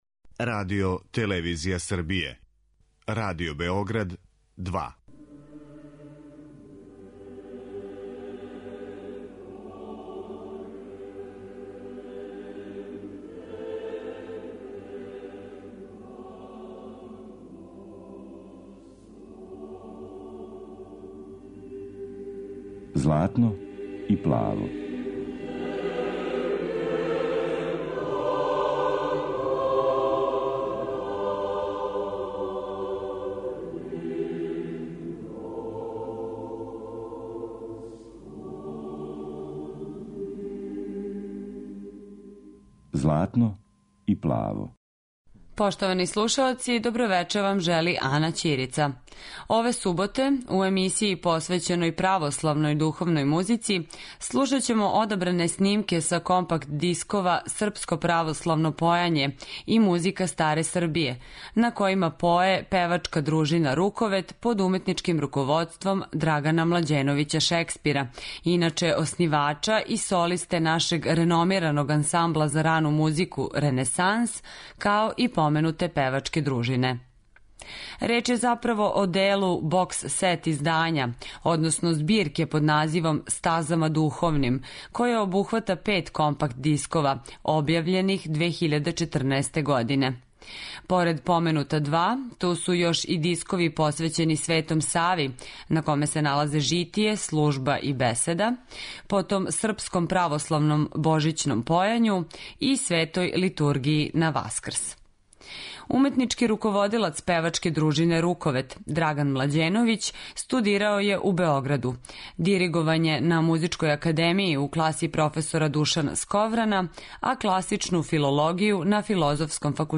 Слушаћете српско православно појање
У овонедељној емисији православне духовне музике, cлушаћете српско православно појање и музику старе Србије у тумачењу певачке дружине Руковет.